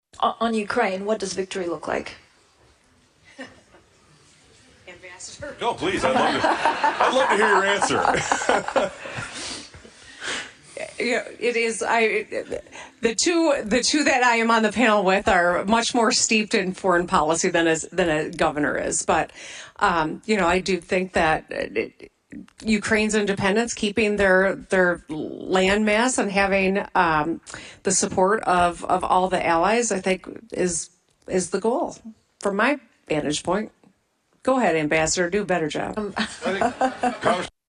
Michigan Gov. Gretchen Whitmer was also at the Munich Security Conference.